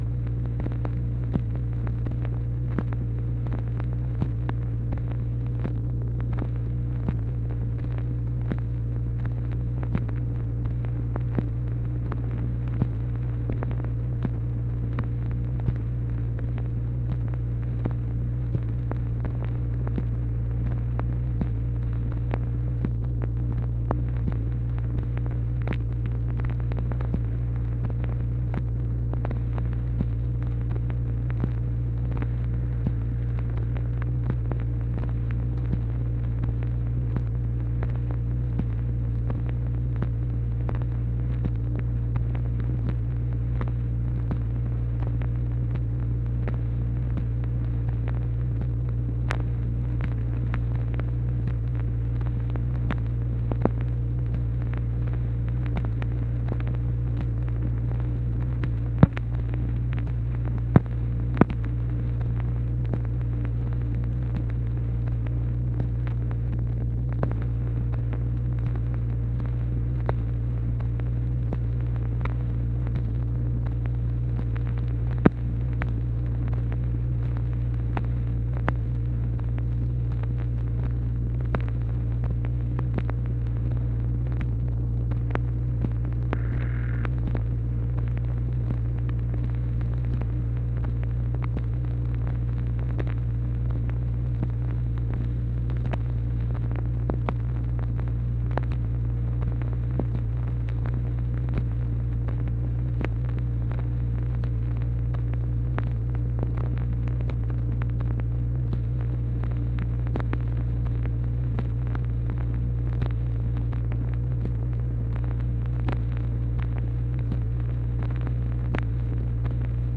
MACHINE NOISE, January 2, 1964
Secret White House Tapes | Lyndon B. Johnson Presidency